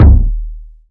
KICK.143.NEPT.wav